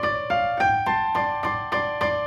Index of /musicradar/gangster-sting-samples/105bpm Loops
GS_Piano_105-D2.wav